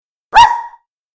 子犬の鳴き声